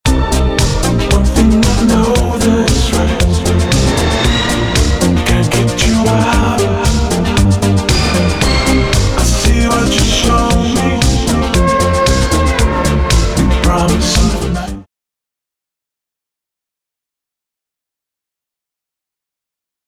• Качество: 320, Stereo
мужской голос
Synth Pop
Electronic
Modern Rock